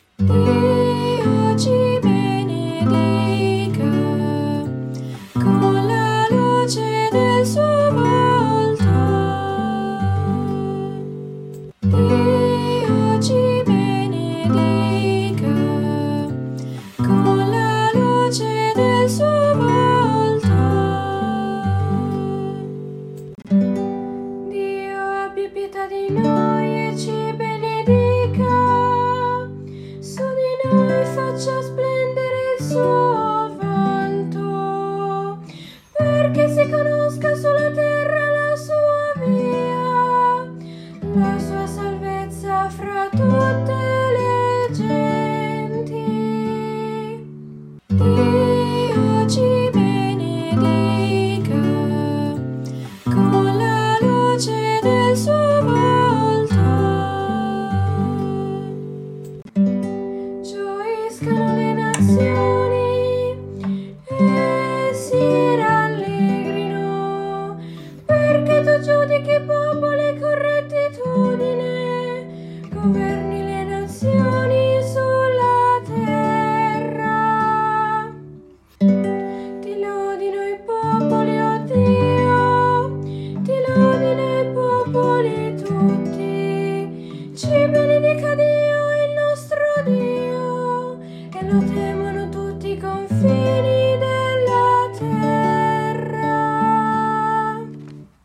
Audio esecuzione chitarra con strofe
OttavaNatale-Proposta-unica-chitarrastrofe.mp3